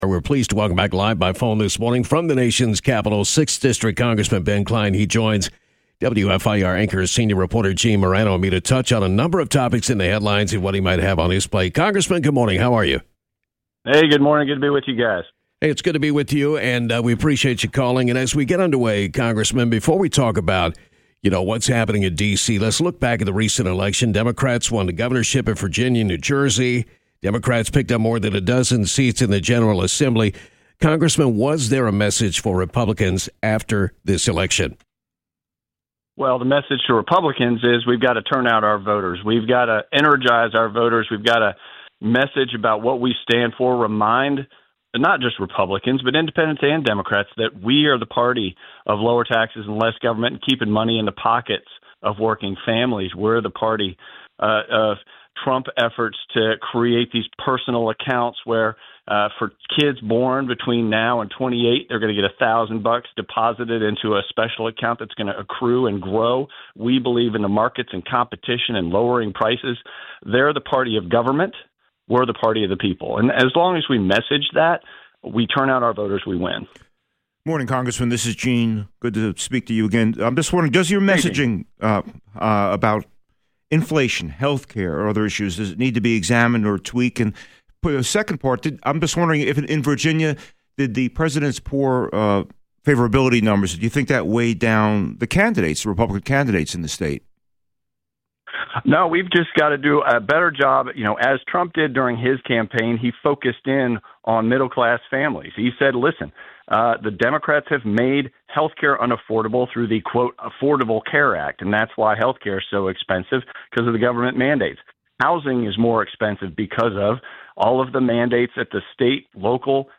Live by phone from the nation’s capitol this morning, 6th District Republican Congressman Ben Cline joined us to touch on a number of topics in the headlines (health care, budget bill, the economy, etc.) – and what he might have on his plate.